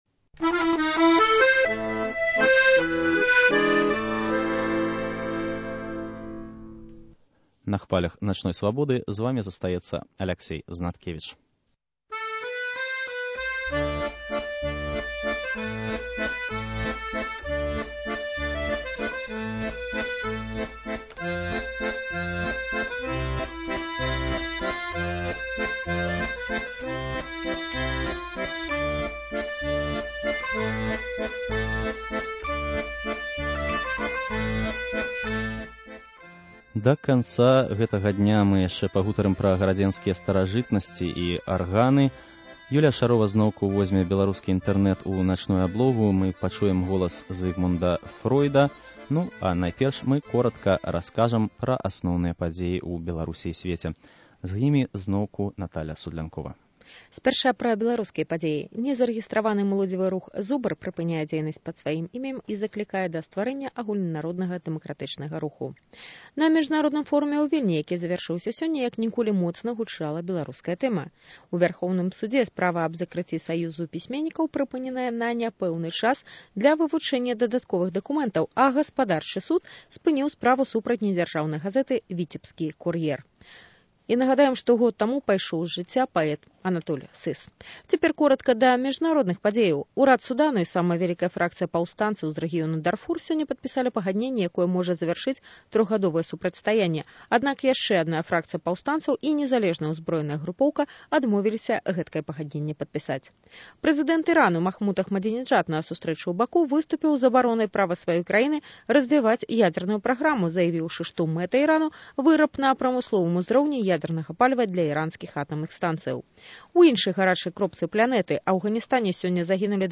Аўдыё mp3 (6.9 Мэгабайт) Real Audio (4.5 Мэгабайт) * Жывы рэпартаж з гістарычнага цэнтру Горадні, дзе ідзе рэканструкцыя * Гукі гарадзенскага фэсту арганнай музыкі. * Архіўны запіс Зыгмунда Фройда.